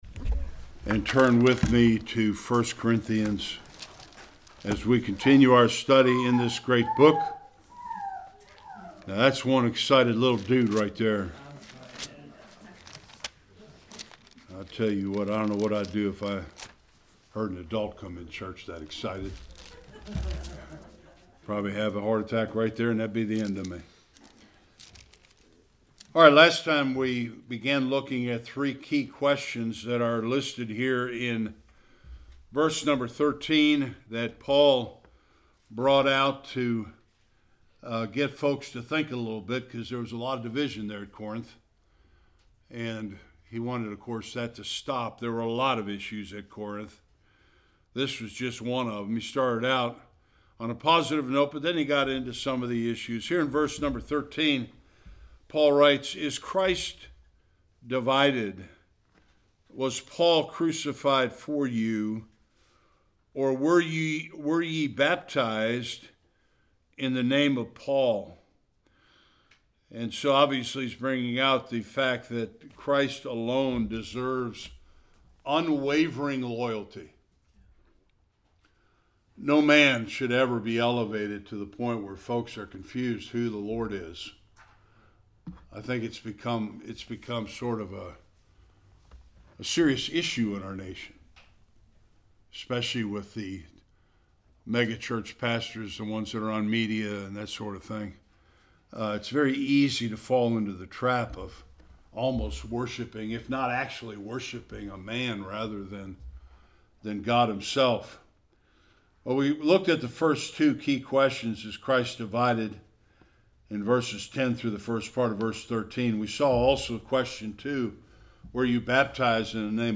Service Type: Sunday Worship